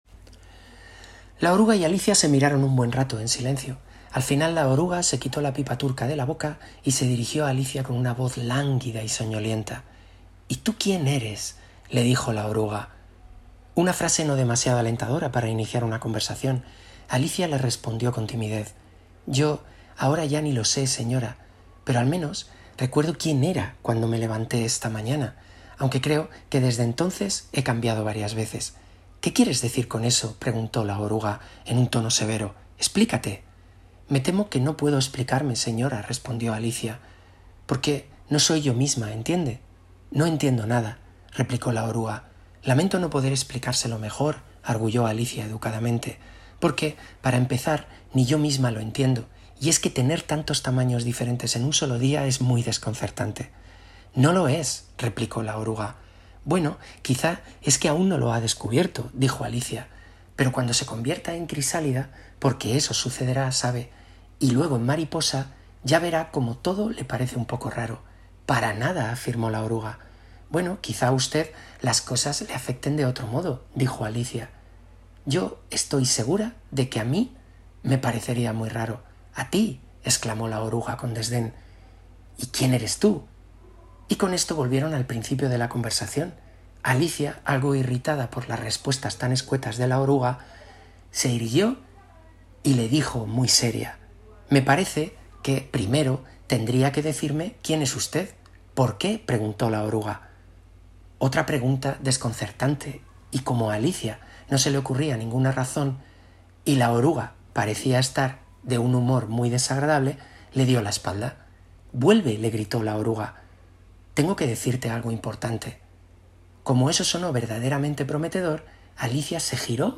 Fragmento de cuento narrado